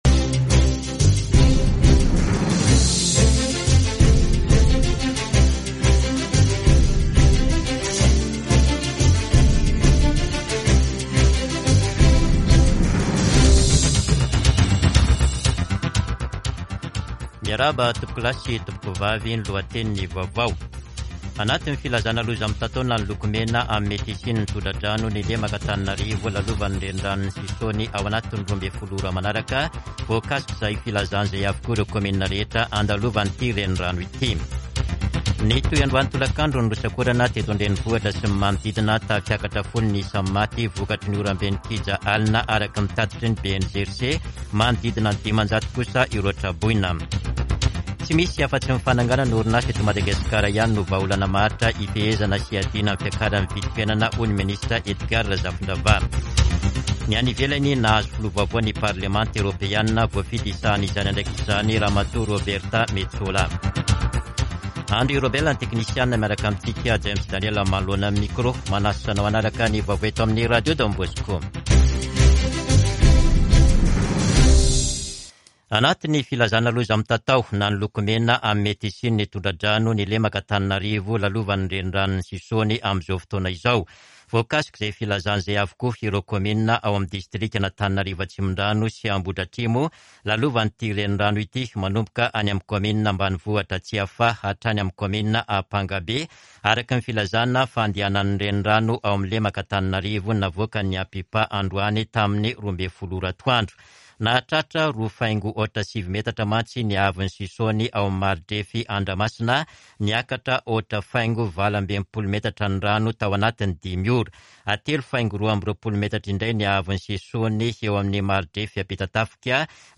[Vaovao hariva] Talata 18 janoary 2022